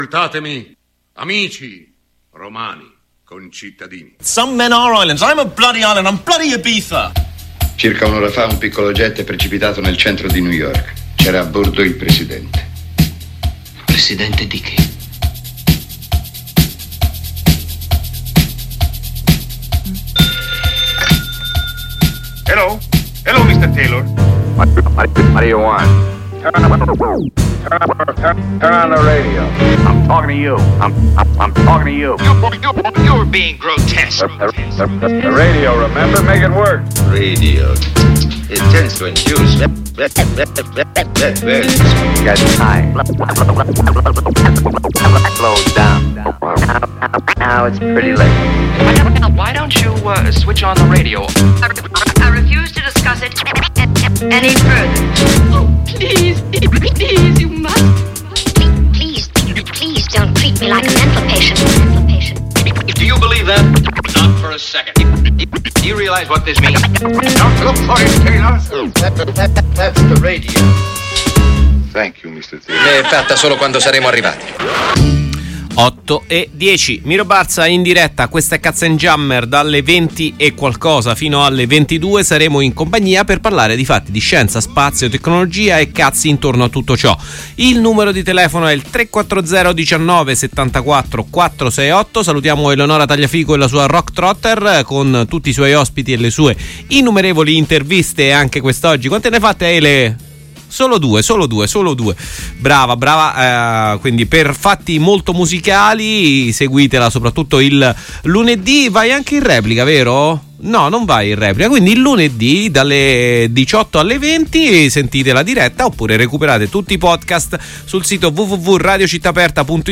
Notizie di salute, di malattie che riappaiono, HIV, morbillo, rosolia. I danni e gli studi sugli scienziati nello spazio, gli esperimenti dell’ASI sulla stazione spaziale orbitante. La musica selezionata